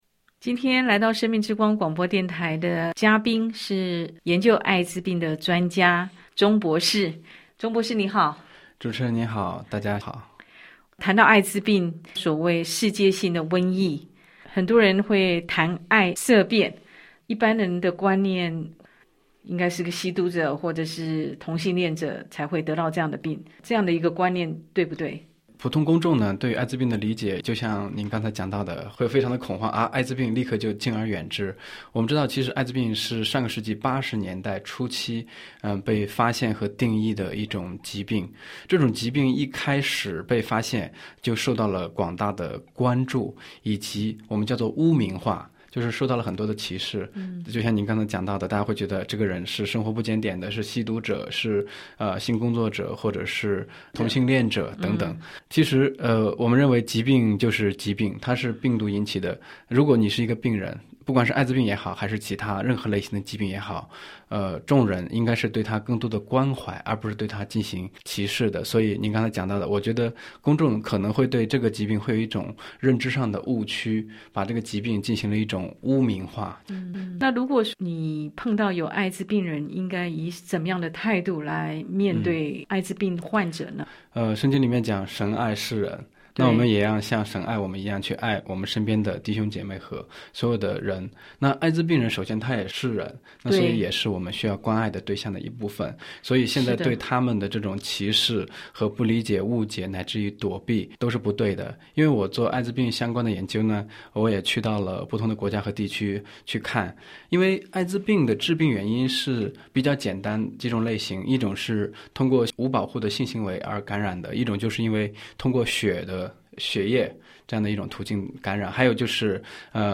【訪談節目】艾滋的愛之與惡之